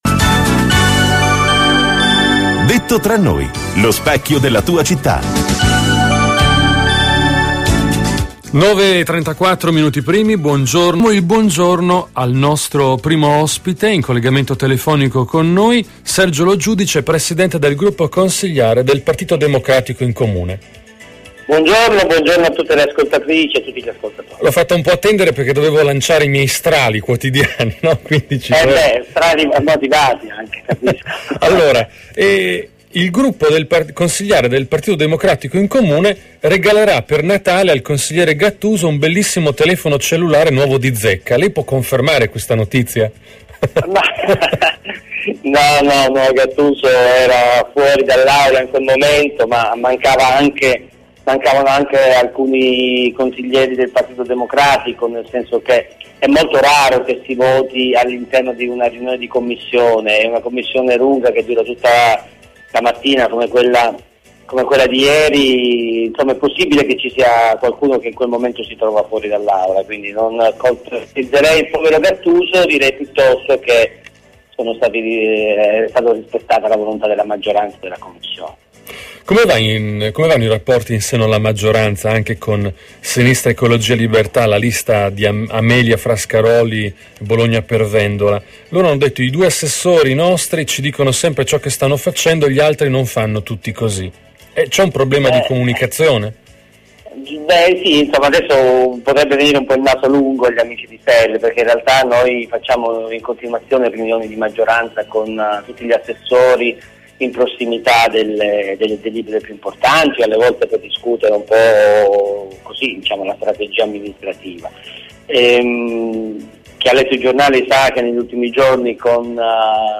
Intervista Radio Tau del capogruppo PD Sergio Lo Giudice il 29 novembre